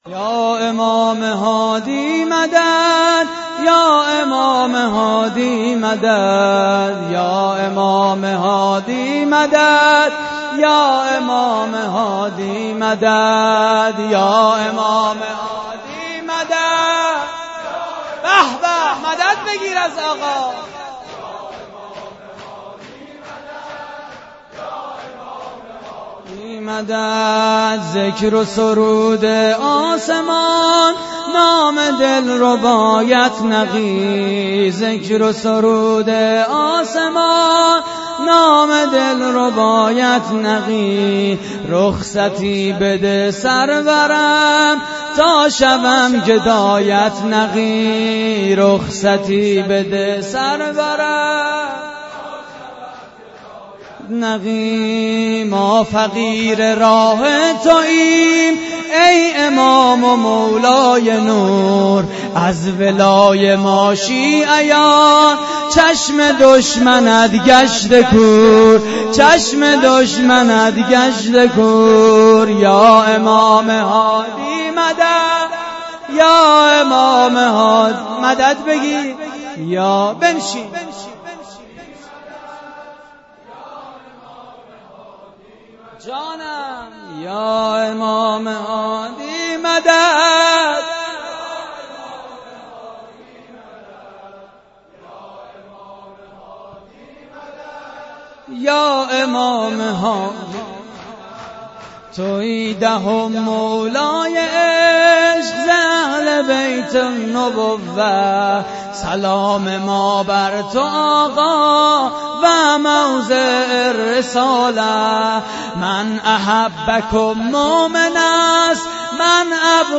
شور: یا امام هادی مدد
مراسم عزاداری شهادت امام هادی (ع)
مسجد لولاگر